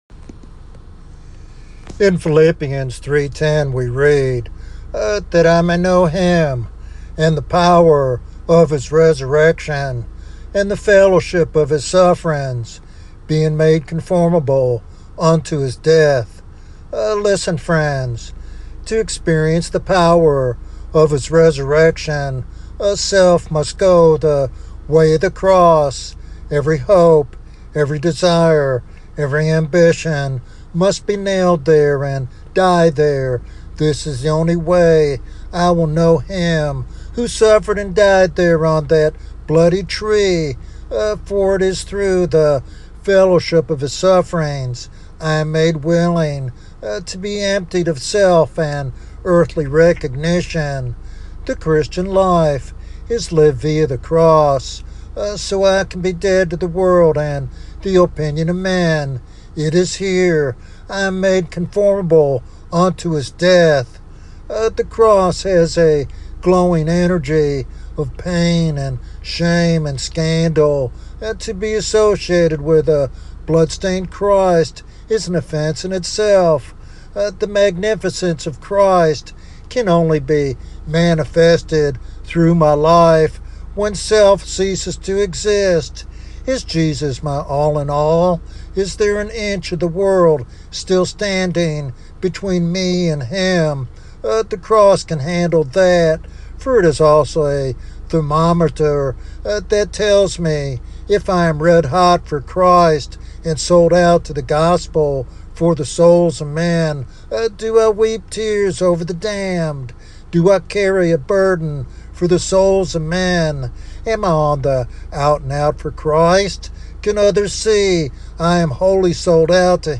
This devotional sermon calls listeners to examine their commitment and to actively share the gospel with urgency and compassion.
Sermon Outline